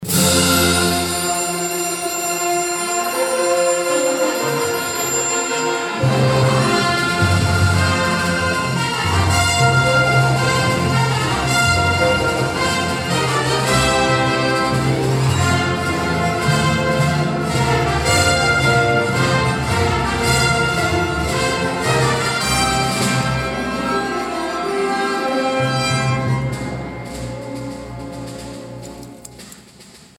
The Granada Theatre was popping Tuesday evening thanks to the Emporia Symphony Orchestra.
The orchestra was back on stage for the first time in over 19 months for its annual pops concert. The evening included several medlies from popular films and television series such as Moana, James Bond, Fantastic Beasts, Downton Abbey and a few selections from a galaxy far, far away.